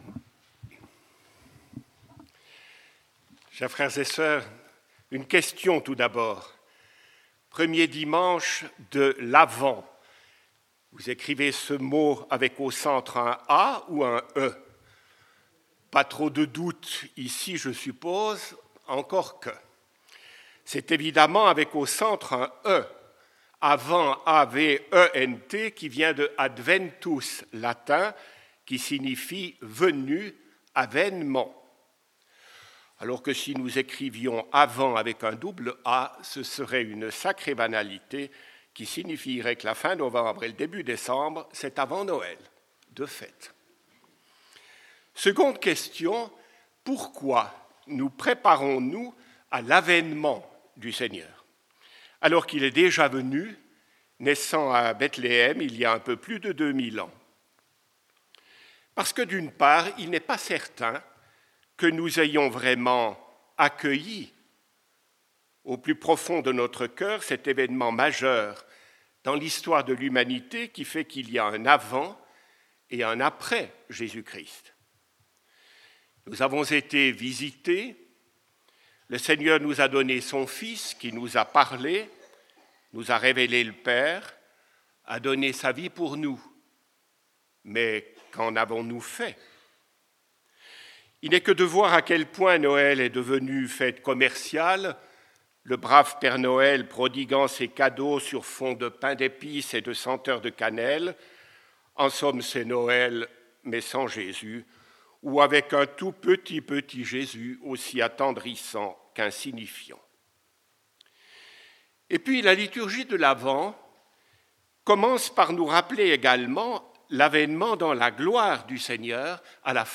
Pour ce premier dimanche de l'Avent